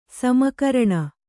♪ sama karaṇa